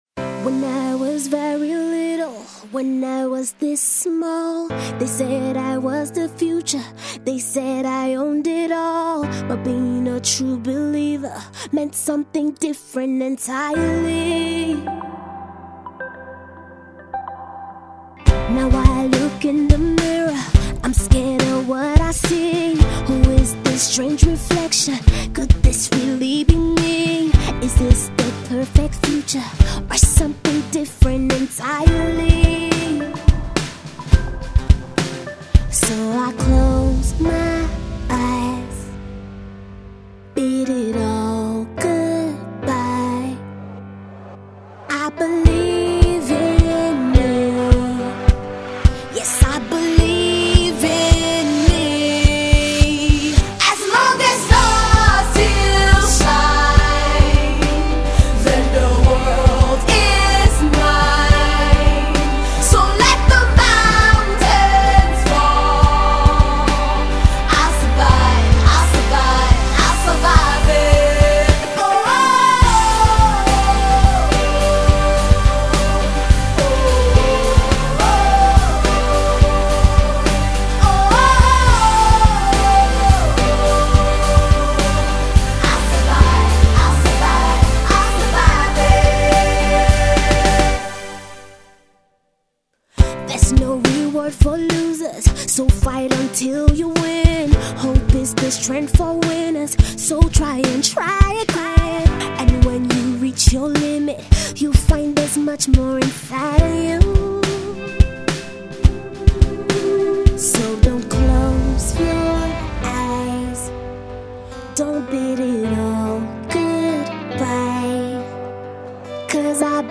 soft vocals
upbeat tune
live strings